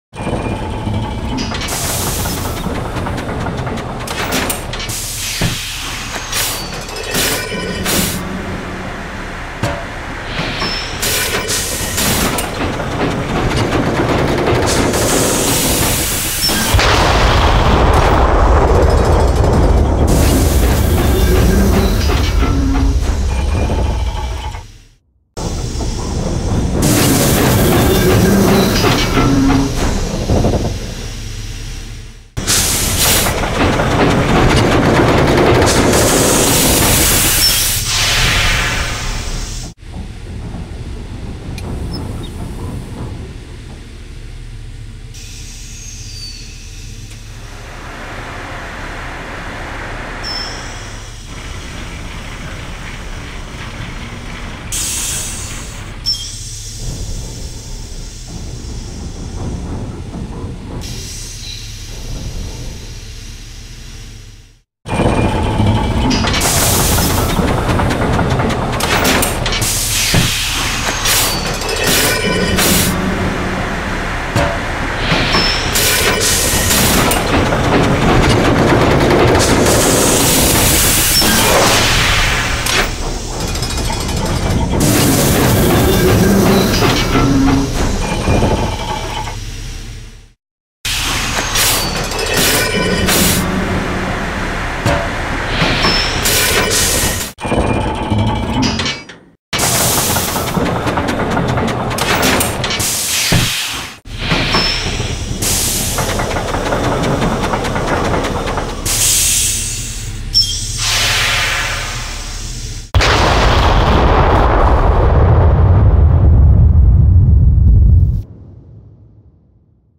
Sound Industry (Industria).mp3